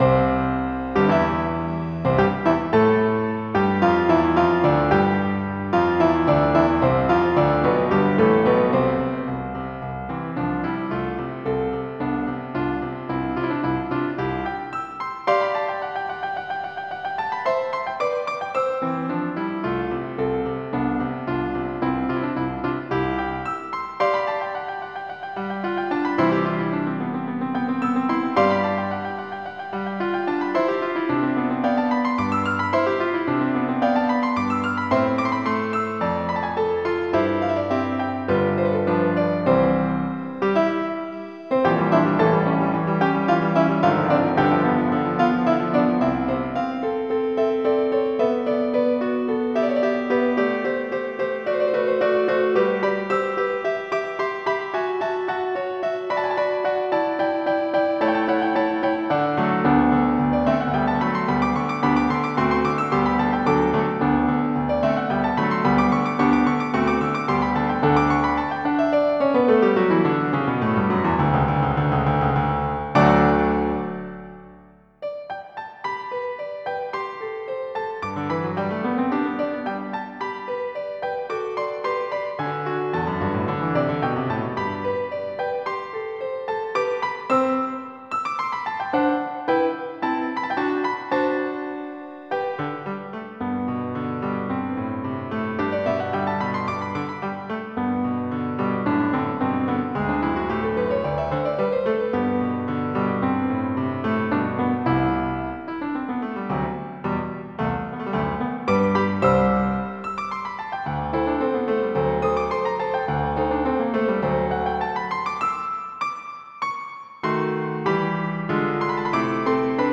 General MIDI